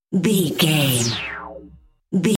Bright Implode
Sound Effects
magical
mystical